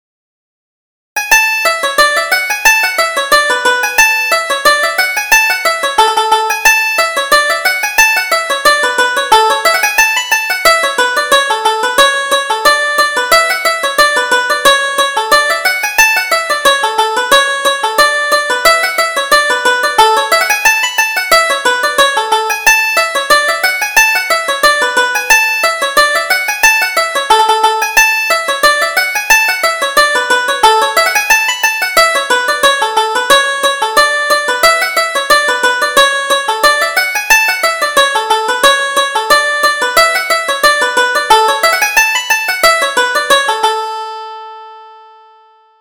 Reel: The Clock in the Steeple